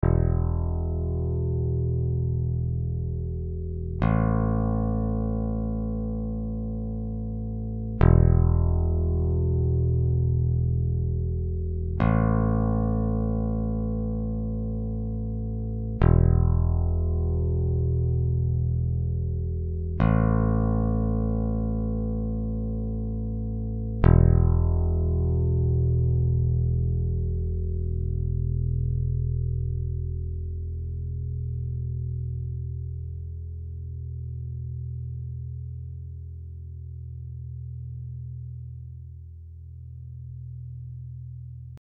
Pro porovnání je to brnkání prázdné E a A struny (jde to E, A, E, A, E, A, E a dozvuk). Obojí samozřejmě čistý signál z basy do zvukovky, bez úprav.
E struna - hra trsátkem
Perfektně je to slyšet u toho trsátka.